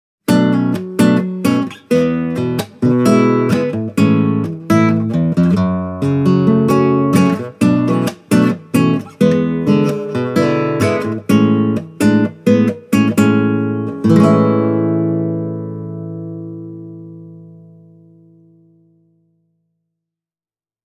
Esteve’s 4ST has a huge and deep tone, which is warm and well-balanced. The instrument’s attack is clear and precise.